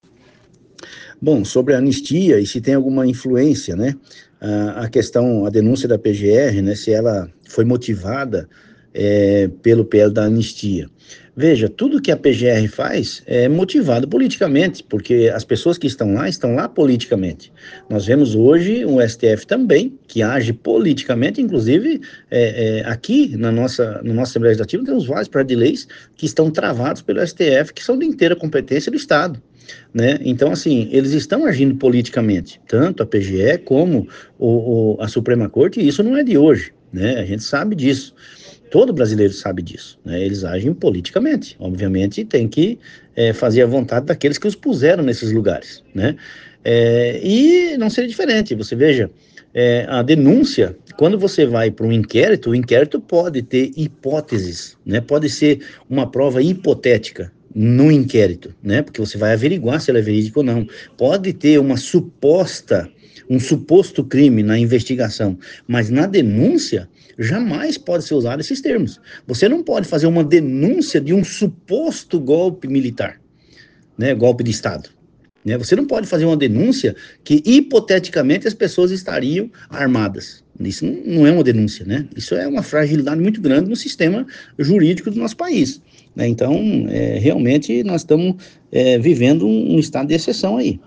OUÇA A ENTREVISTA DO DEPUTADO GILBERTO CATTANI